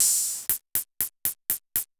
Index of /musicradar/ultimate-hihat-samples/120bpm
UHH_ElectroHatB_120-03.wav